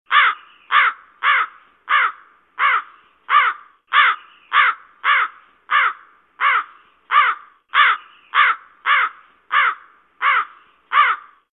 Tiếng Quạ kêu mp3